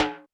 Drums_K4(31).wav